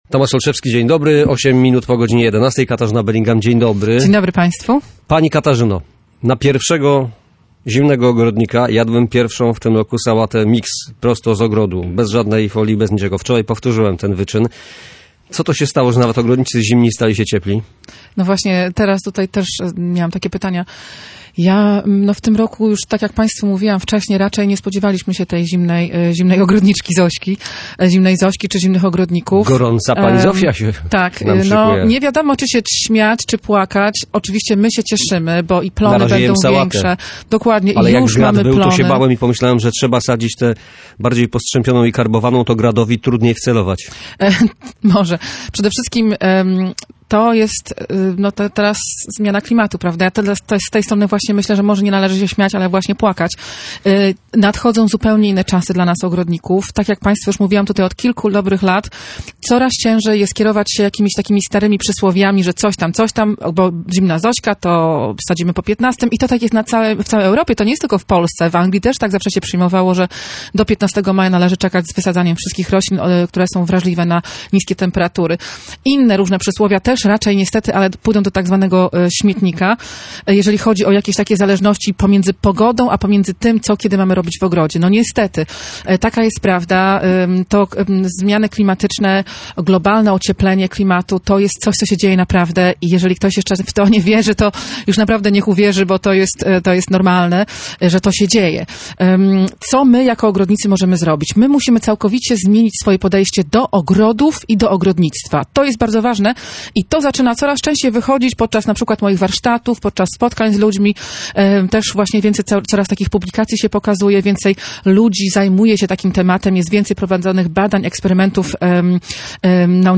Ekspertka o problemach związanych z pogodą